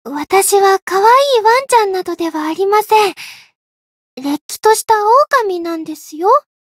灵魂潮汐-伊汐尔-互动-不耐烦的反馈1.ogg